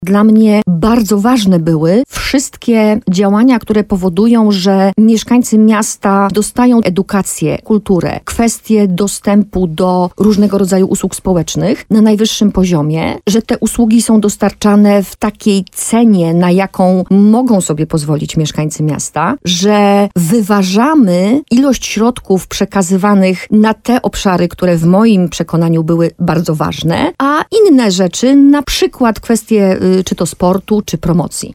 – Rzeczywiście od kilku miesięcy był między nami pewien rozdźwięk w tej kwestii – powiedziała Magdalena Majka w programie Słowo za Słowo na antenie radia RDN Nowy Sącz.